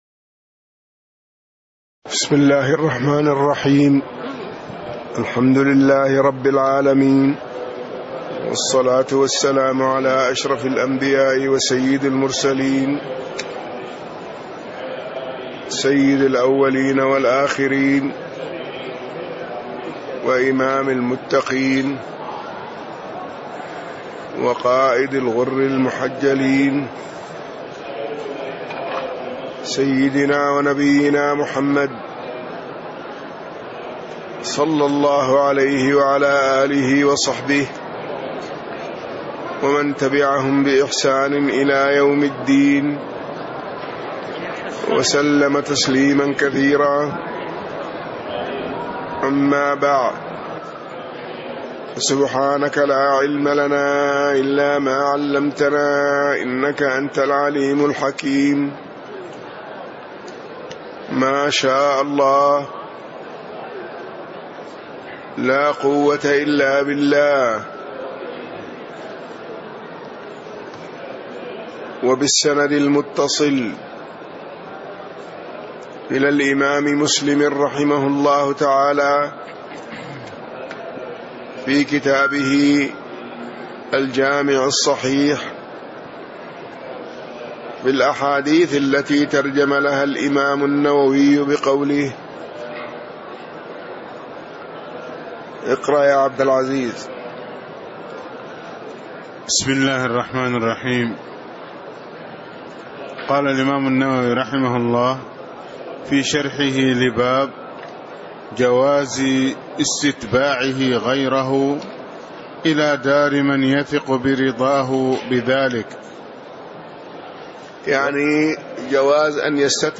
تاريخ النشر ٦ شعبان ١٤٣٦ هـ المكان: المسجد النبوي الشيخ